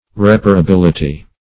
Search Result for " reparability" : The Collaborative International Dictionary of English v.0.48: Reparability \Rep`a*ra*bil"i*ty\ (r?p`?-r?-b?l"?-t?), n. The quality or state of being reparable.